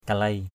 /ka-leɪ/ (đg.) đào = creuser. kalei labang kl] lb/ đào lỗ = creuser un trou.